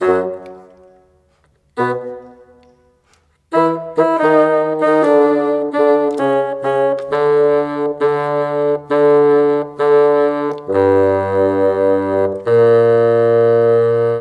Rock-Pop 01 Bassoon 01.wav